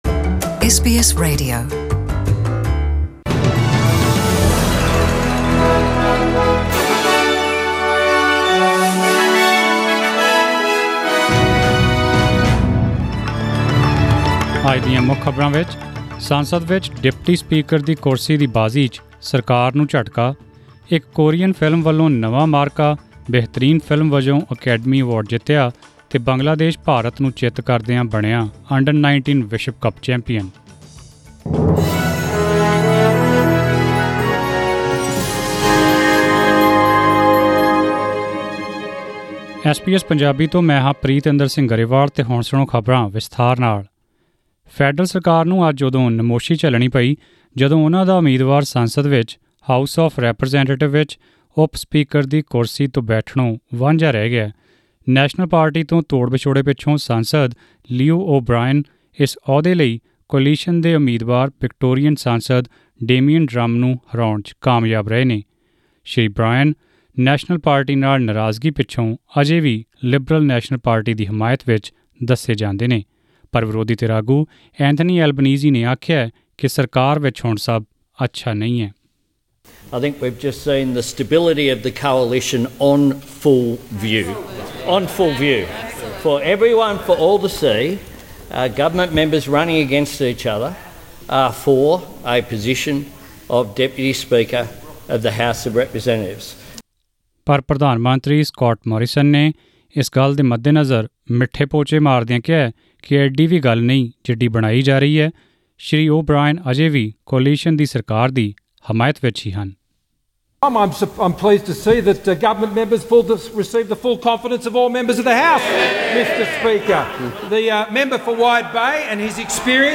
Australian News in Punjabi: 10 February 2020